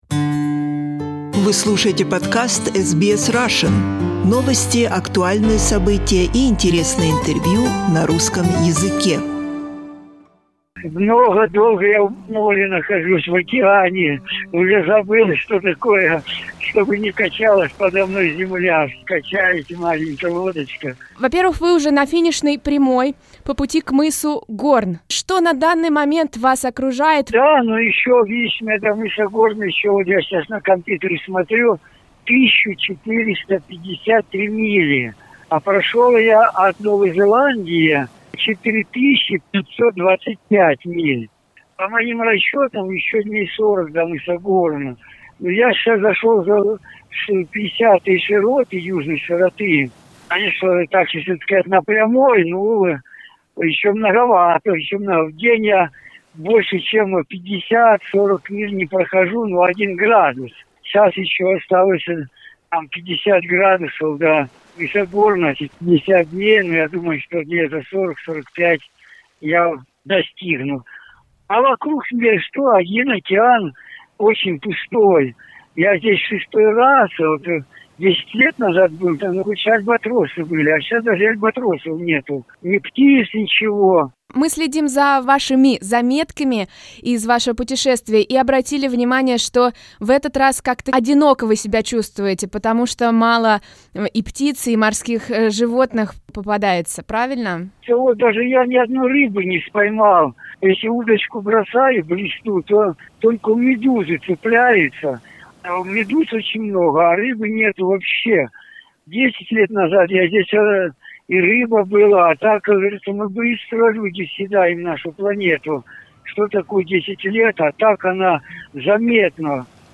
Меньше половины пути первого участка Новая Зеландия-Чили осталось преодолеть путешественнику Федору Конюхову, который решил совершить кругосветное путешествие на весельной лодке. Незадолго до очередной перемены погоды и на исходе 103-го дня в Южном океане, мы созвонились с Федором.